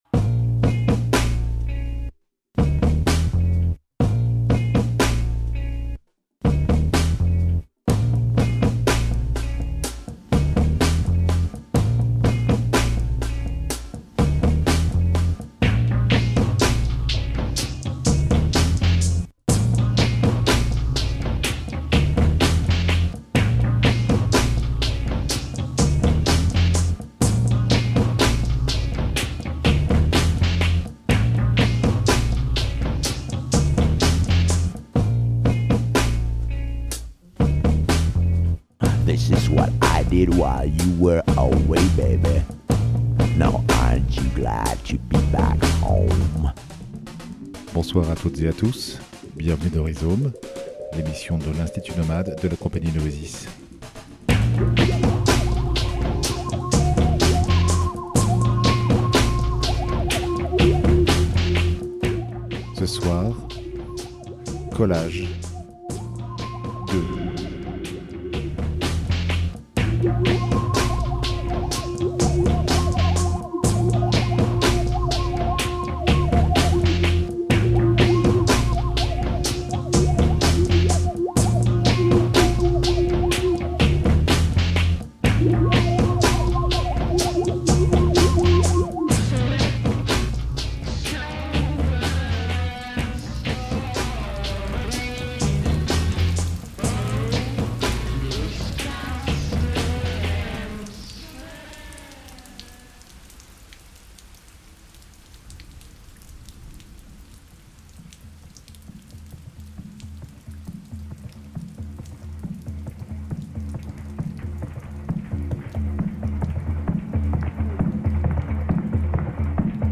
Ce paysage sonore
radio-rhizomes_collages_2_mai-2025.mp3